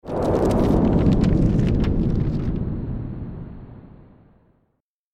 دانلود صدای آتش 26 از ساعد نیوز با لینک مستقیم و کیفیت بالا
جلوه های صوتی
برچسب: دانلود آهنگ های افکت صوتی طبیعت و محیط دانلود آلبوم صدای شعله های آتش از افکت صوتی طبیعت و محیط